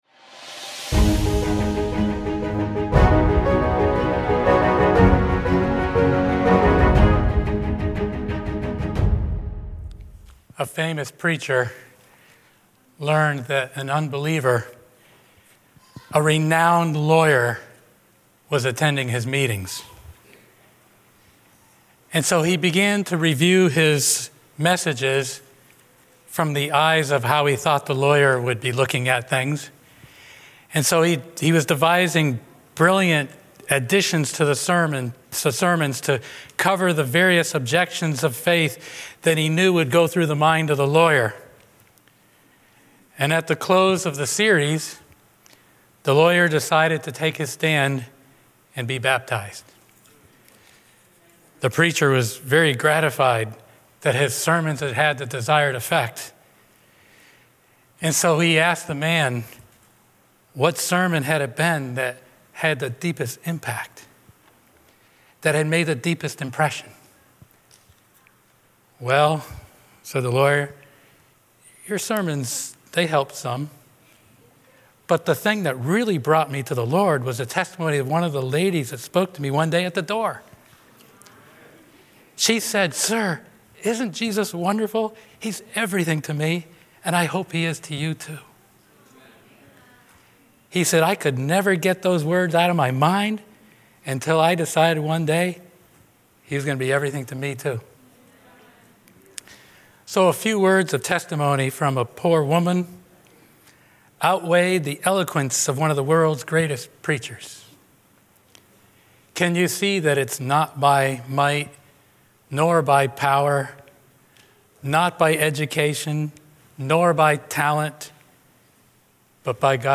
A message from the series "Central Sermons."
From Series: "Central Sermons"